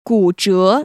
[gŭzhé] 꾸저